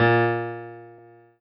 piano-ff-26.wav